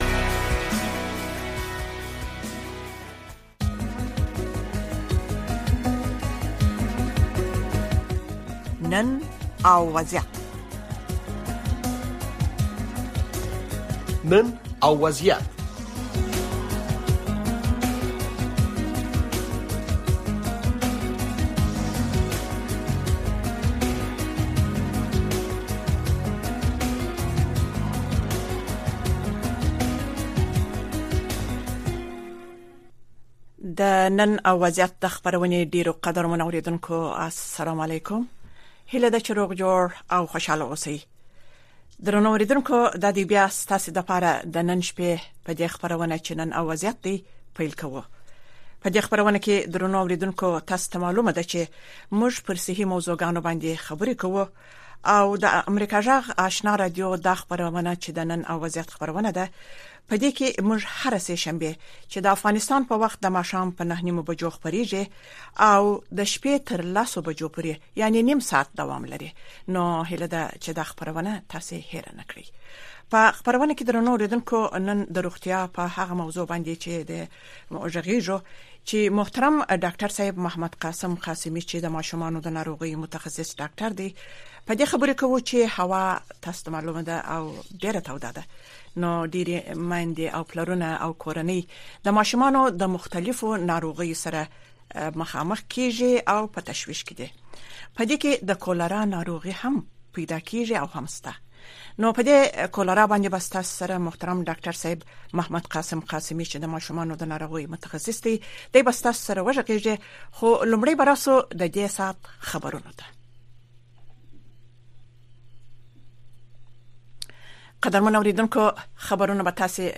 د نړۍ سیمې او افغانستان په روانو چارو او د نن په وضعیت خبرونه، راپورونه، مرکې او تحلیلونه